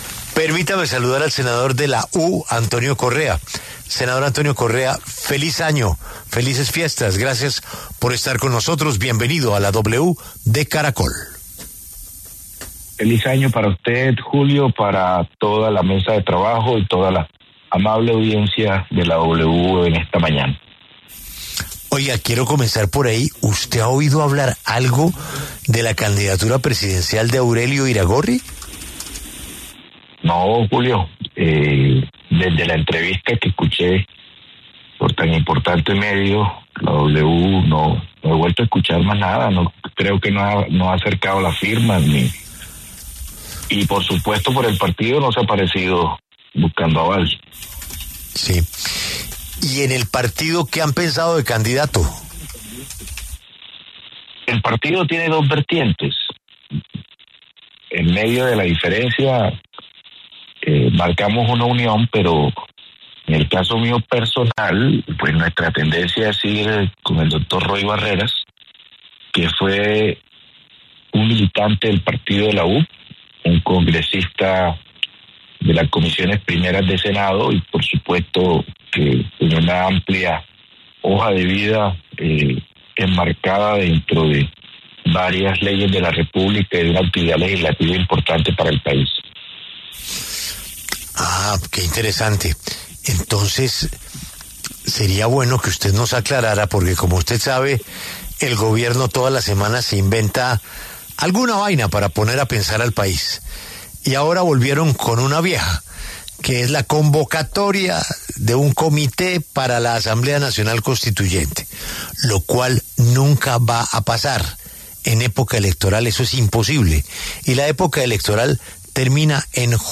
Los senadores Antonio Correa, del Partido de la U, y Carlos Fernando Motoa, de Cambio Radical, pasaron por los micrófonos de La W.